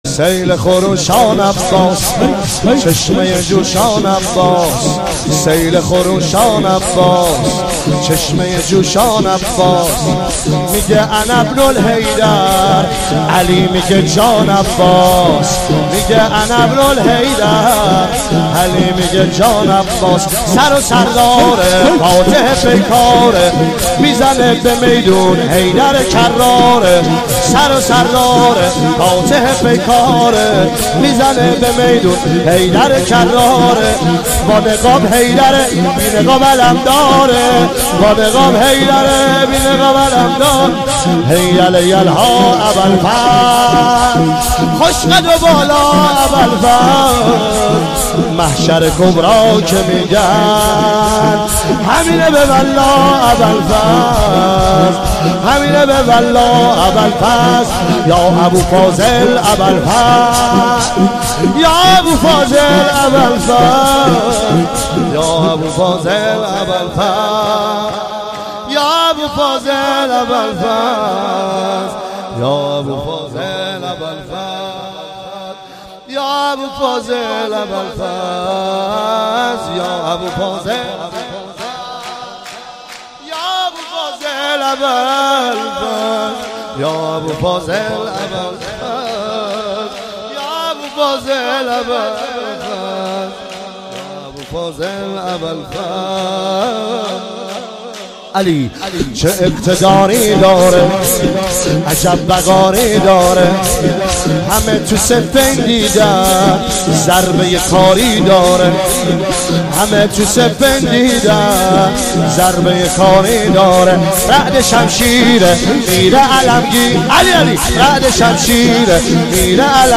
عنوان جشن میلاد سرداران کربلا
مداح
سرود-شور